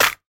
Clap (Remastered version of that clap everyone steals).wav